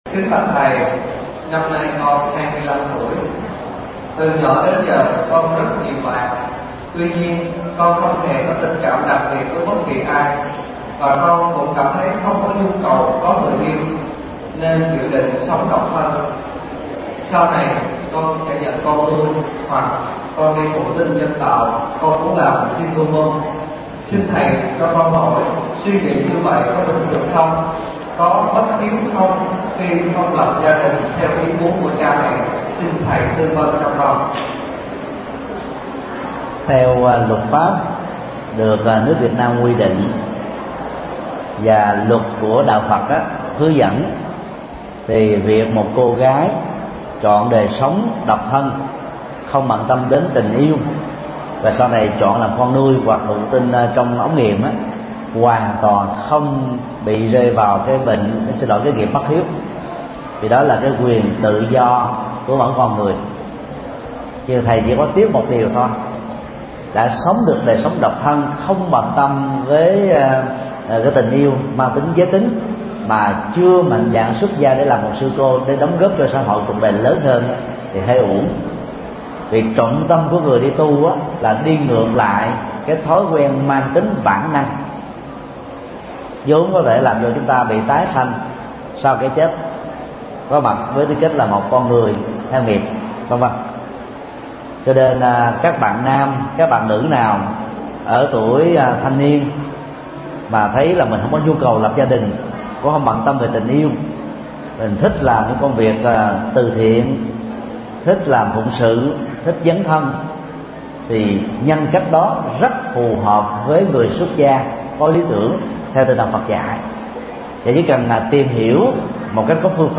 Vấn đáp: Tâm lý đời sống độc thân – Thầy Thích Nhật Từ mp3